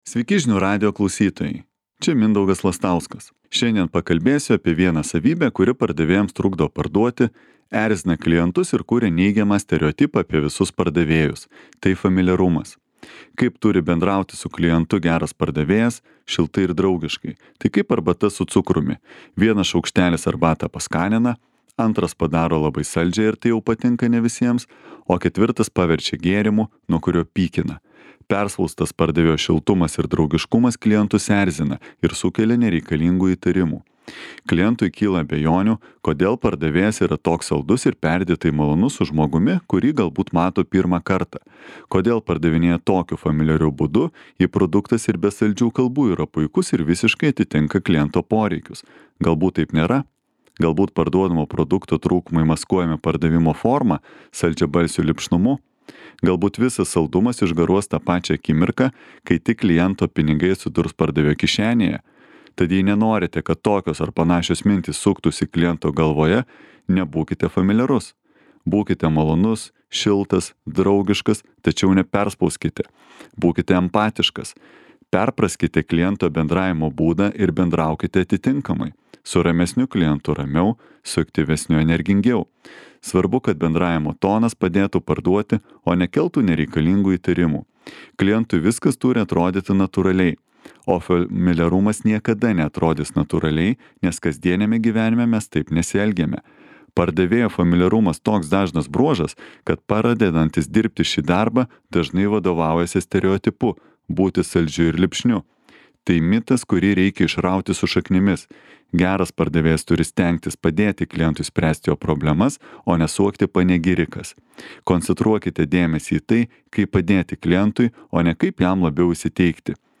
komentaras.